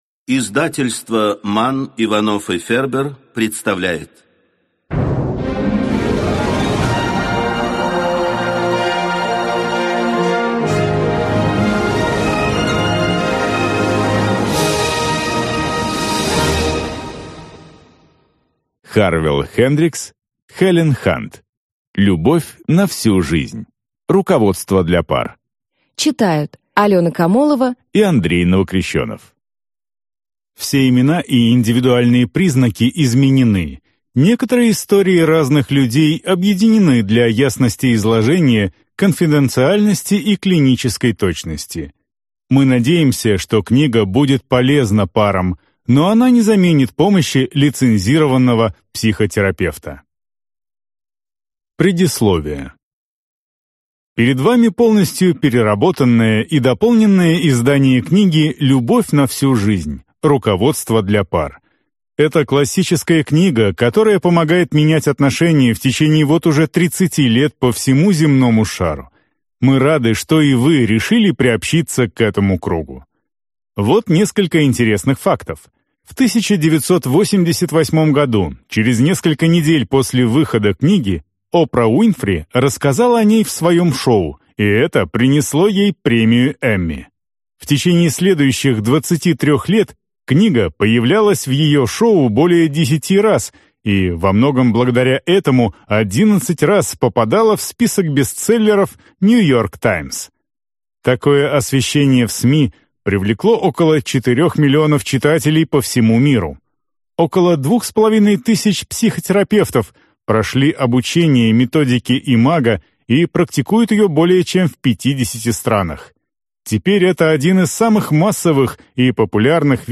Аудиокнига Любовь на всю жизнь | Библиотека аудиокниг